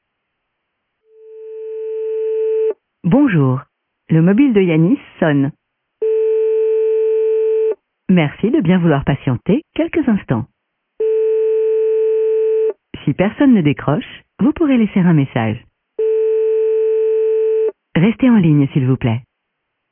Message Classique: Veuillez Patienter